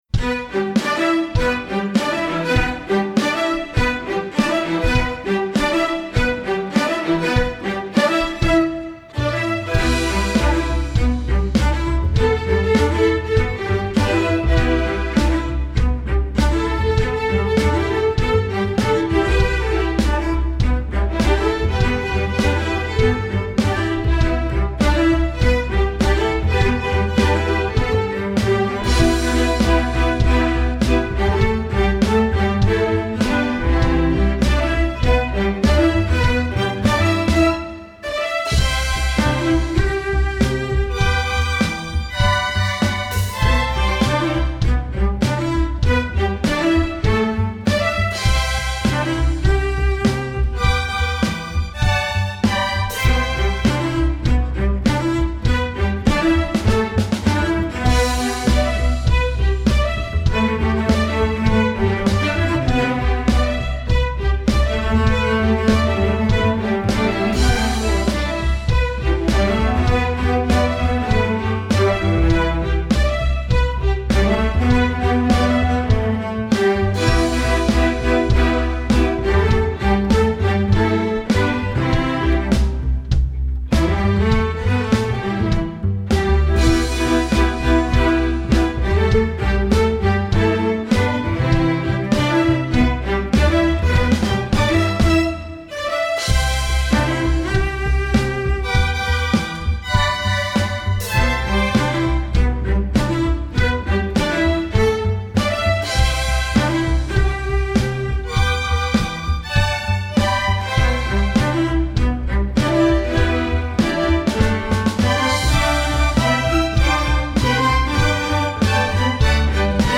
Instrumentation: string orchestra
pop, rock
Drums part: